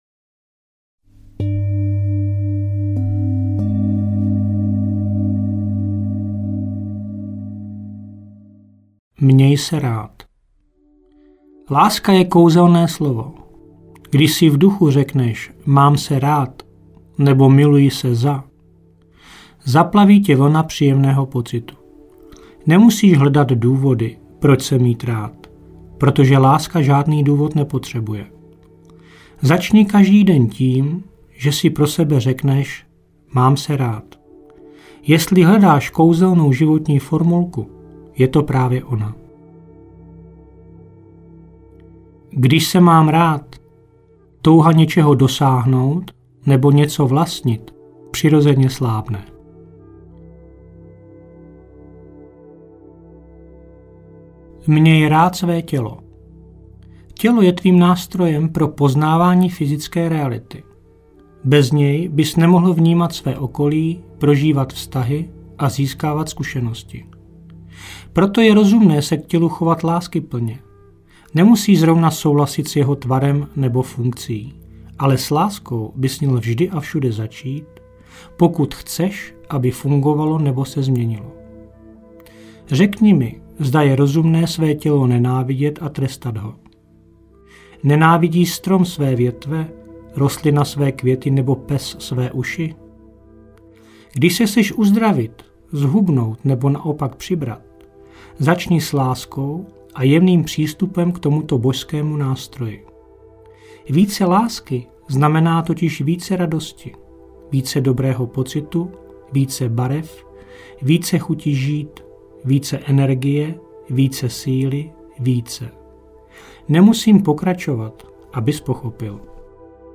Vnitřní štěstí audiokniha
vnitrni-stesti-audiokniha